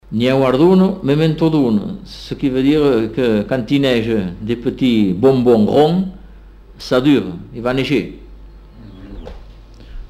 Aire culturelle : Comminges
Lieu : Bagnères-de-Luchon
Effectif : 1
Type de voix : voix d'homme
Production du son : récité
Classification : proverbe-dicton